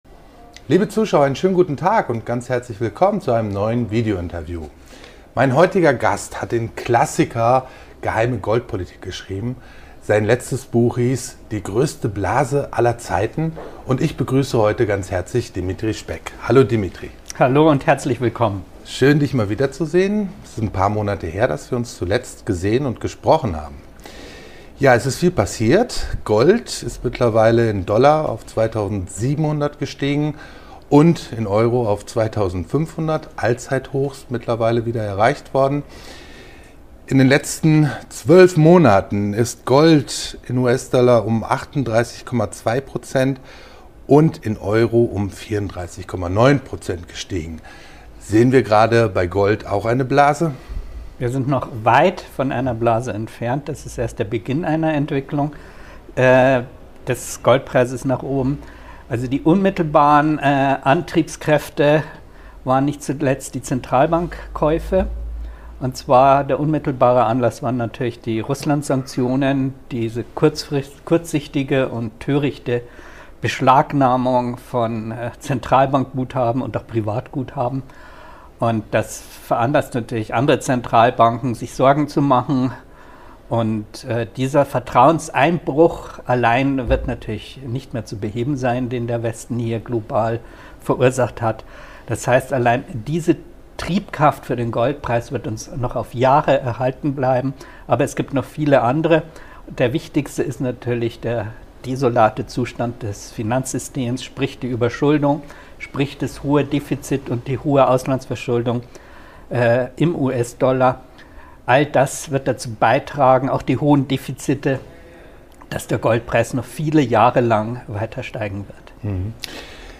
Im Rahmen unseres Kapitaltags am 25. Oktober 2024